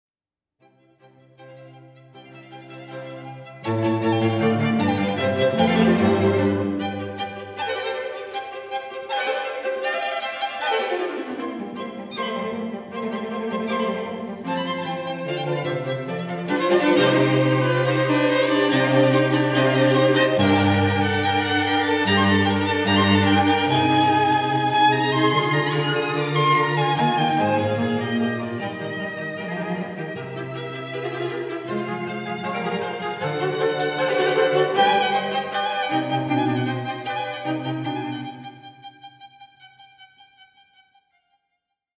Rondo: Presto